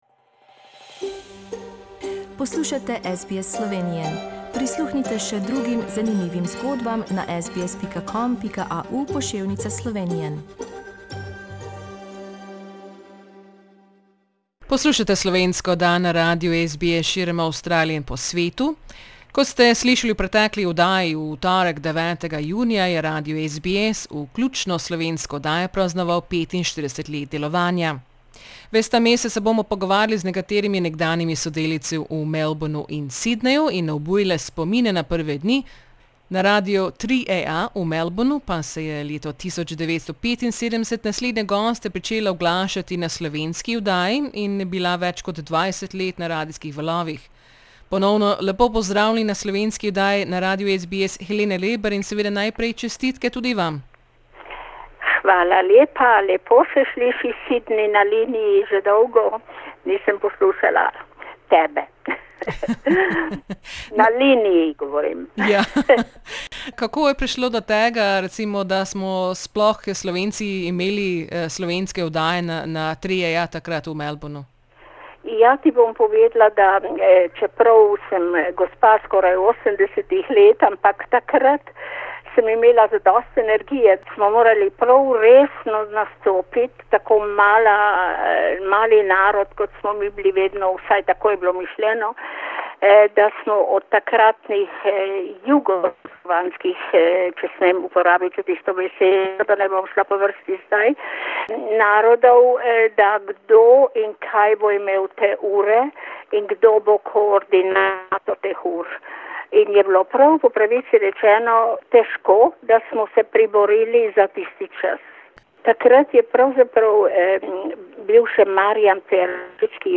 v tem pogovoru obuja spomine na tiste čase